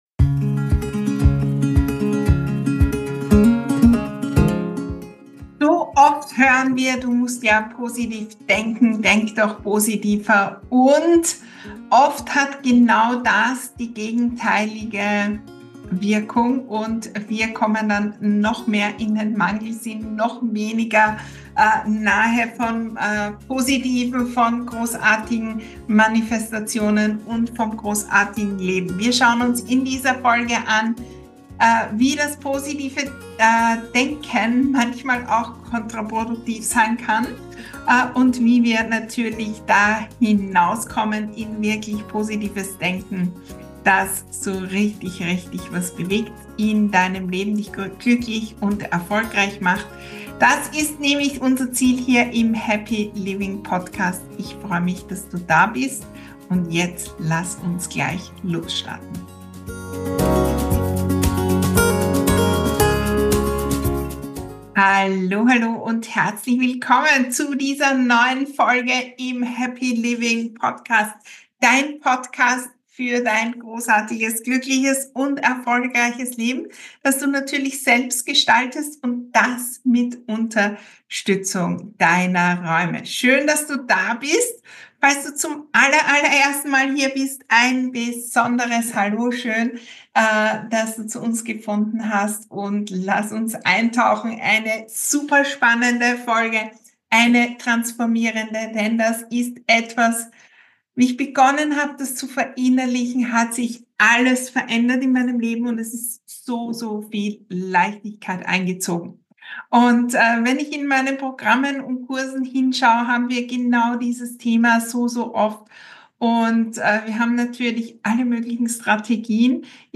217: Das Umstellen meines Zimmers hat mein Leben verändert. Interview